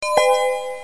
SFXbingo游戏音效下载
SFX音效